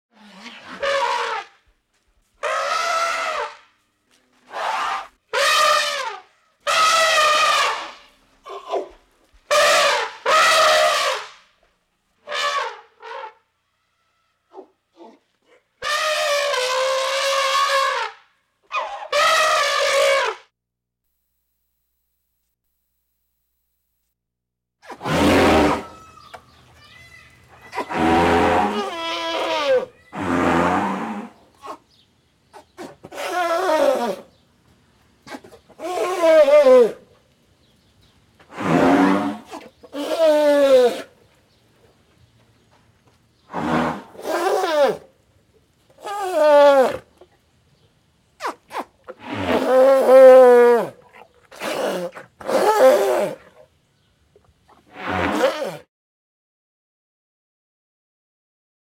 دانلود صدای فیل 12 از ساعد نیوز با لینک مستقیم و کیفیت بالا
جلوه های صوتی